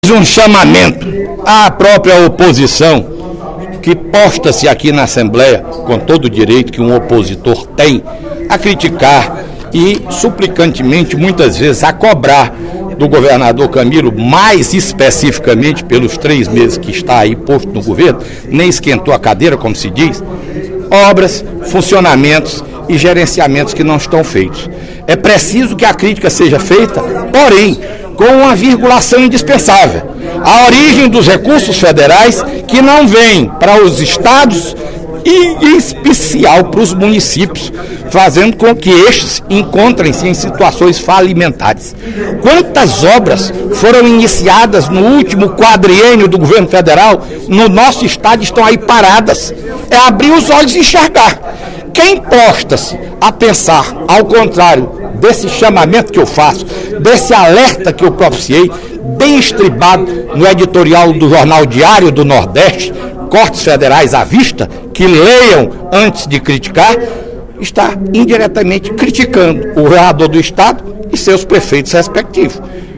Em pronunciamento no primeiro expediente da sessão plenária da Assembleia Legislativa desta sexta-feira (27/03), o deputado Fernando Hugo (SD) destacou editorial publicado hoje, no jornal Diário do Nordeste, que prevê cortes federais e obstáculos na obtenção de recursos da União para estados e municípios.